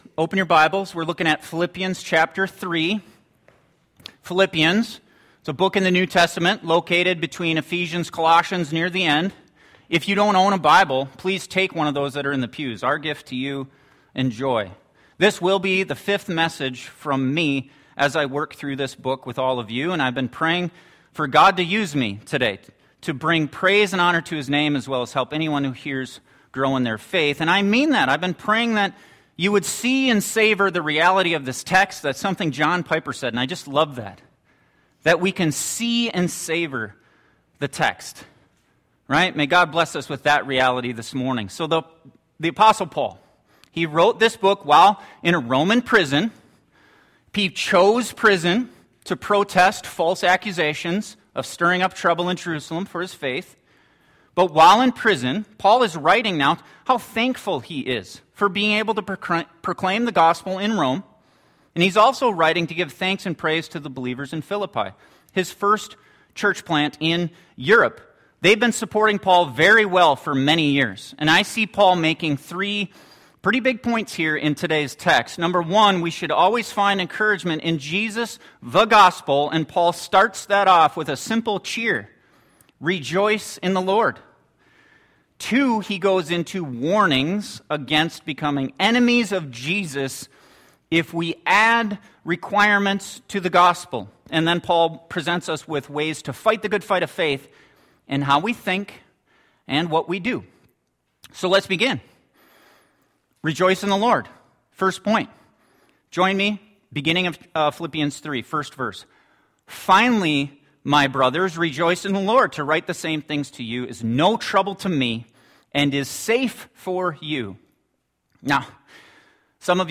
Philippians Passage: Philippians 3 Study through Philippians 3 with this sermon from our Youth Director